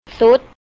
There are some words in Thai, use ท (tor ta-harn) with ร (ror roer) is "ทร-" or ท with ร and ว (wor whaen) is "ทรว-" in front of vowel or spelling will pronounce as ซ (sor soe), namely;
ซุด
sud
sud.mp3